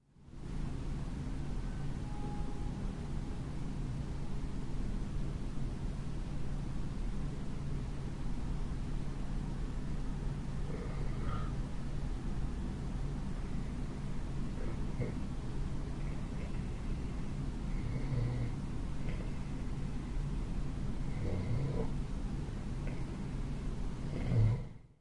一辆卡车正在那里装货和离开。
我睡在三楼，我睡觉时在那里打开了我的EdirolR09。
标签： 身体 呼吸机 现场记录 家庭 自然 噪声 街道 街道噪声 交通
声道立体声